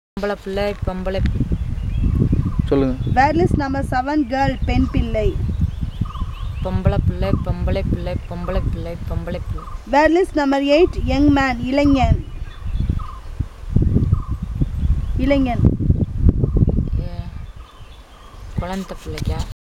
Elicitation of words about stages of life - Part 3